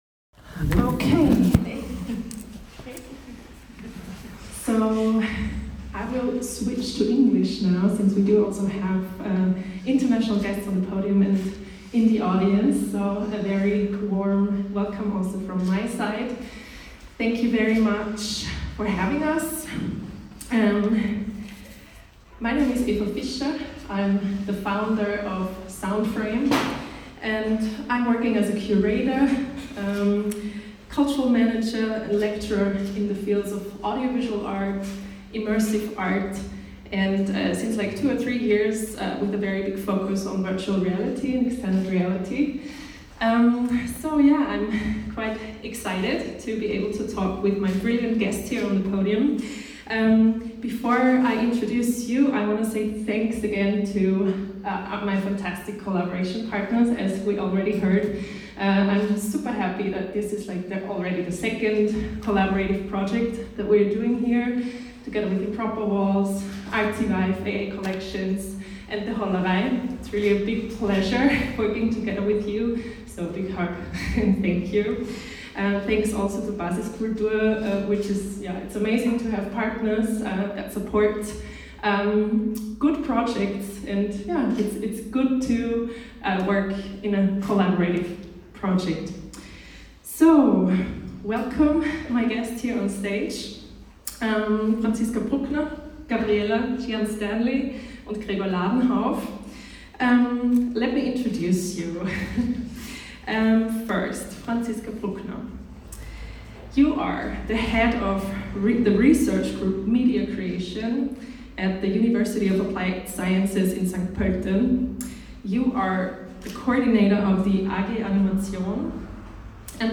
TALKS
walkie-talkie_panel_klein.mp3